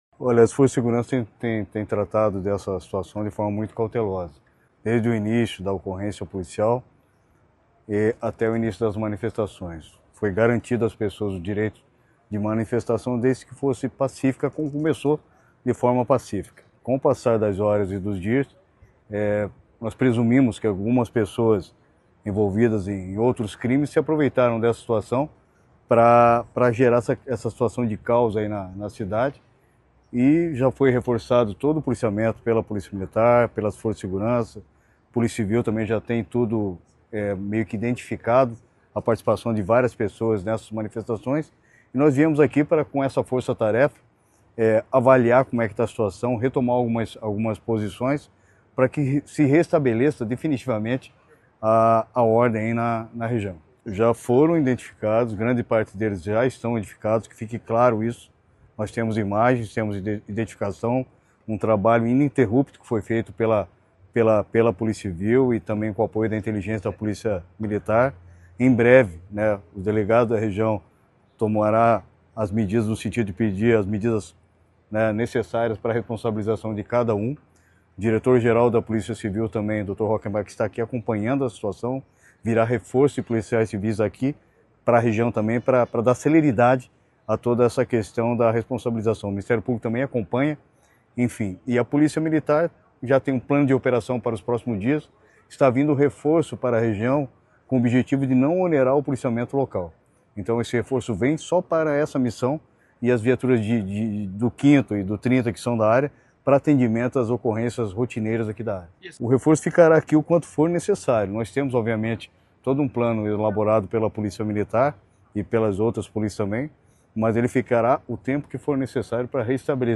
Sonora do secretário da Segurança Pública, Hudson Teixeira, sobre o reforço no policiamento em Londrina após atos de violência